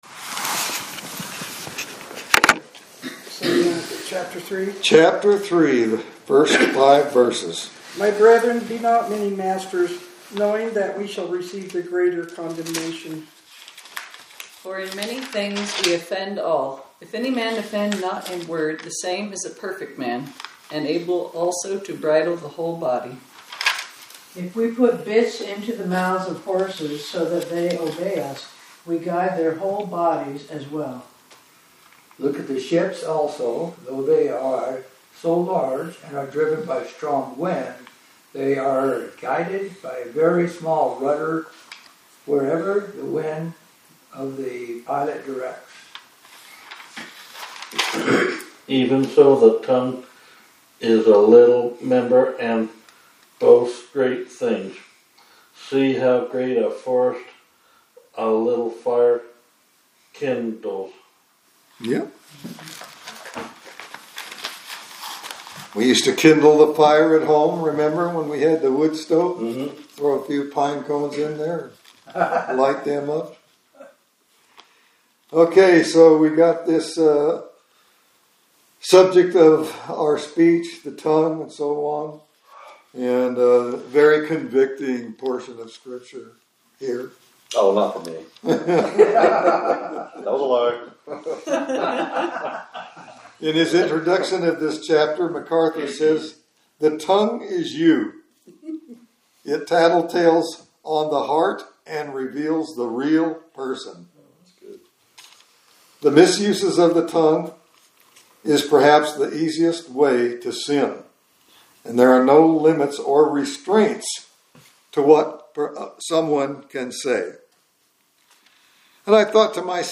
Bible Study from November 11, 2025